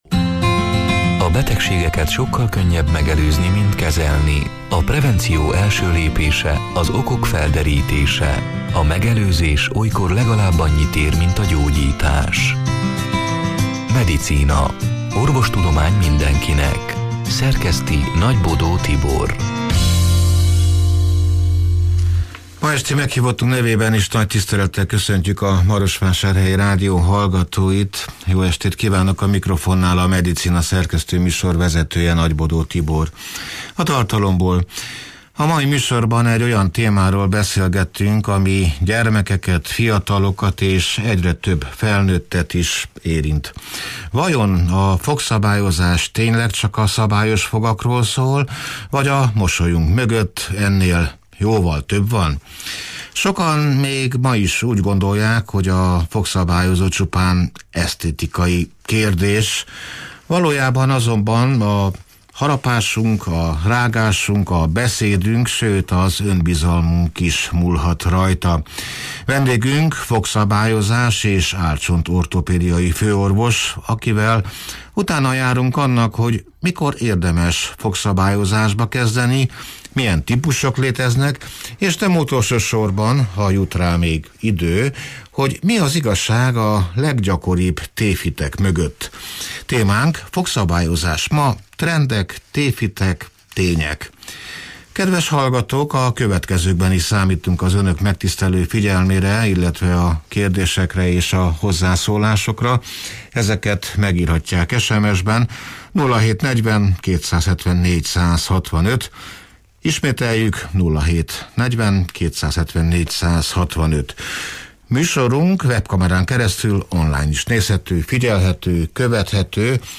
(elhangzott: 2025. november 19-én, szerdán este nyolc órától élőben)